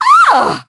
piper_hurt_06.ogg